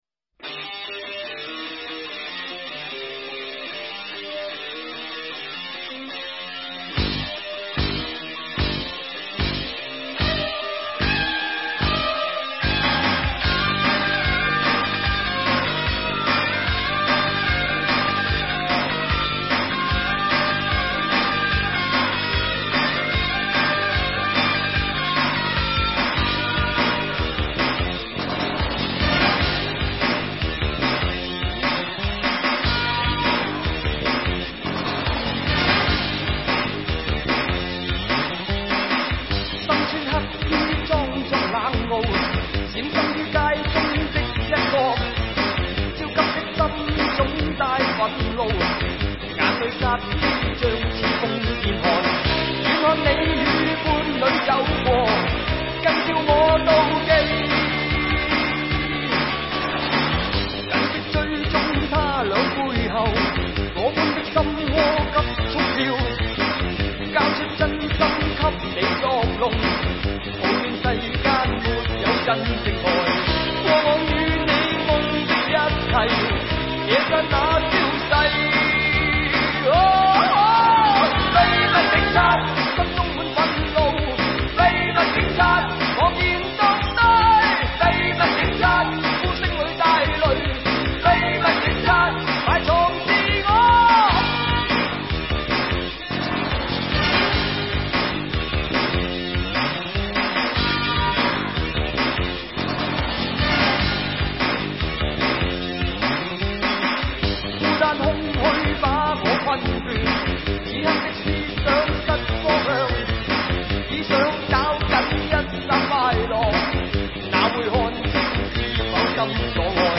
粤语专辑